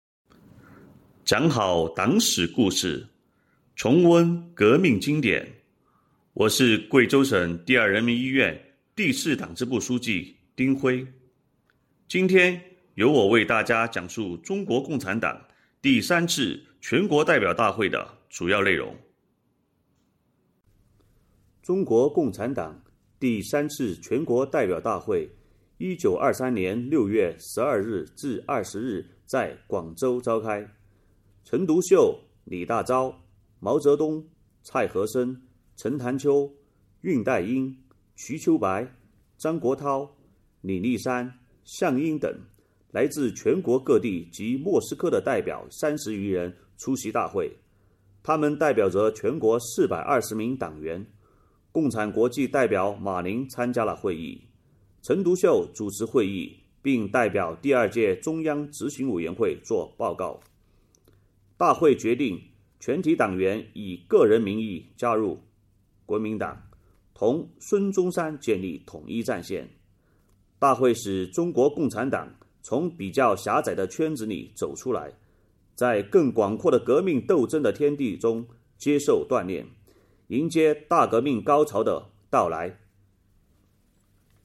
邀请院领导班子成员、支部书记、百优医护、抗疫代表、脱贫攻坚先进个人代表为讲述人，以诵读的形式，讲述党的发展历史，分享重大史事件，感悟初心使命，坚定理想信念。